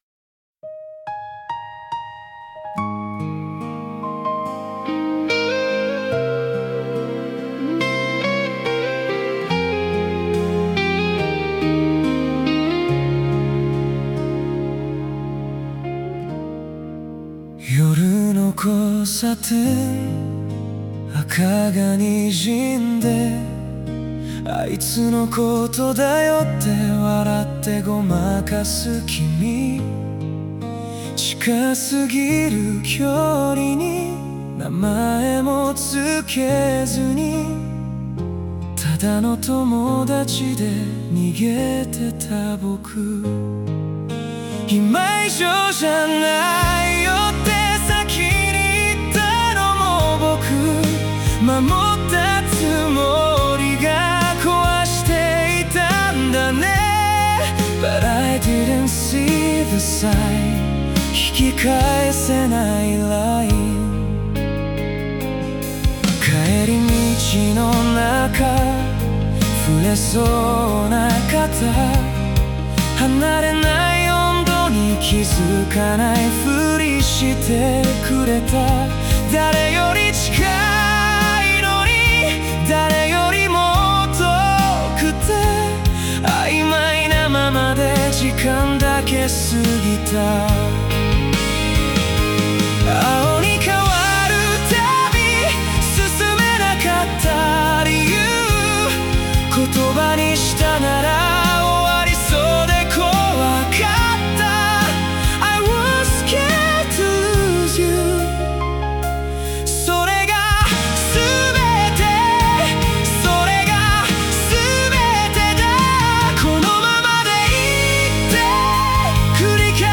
男性ボーカル
イメージ：エモーショナル・バラード,男性ボーカル,切ない,遅すぎた本音